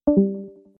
leave_call-DtItyZMg.mp3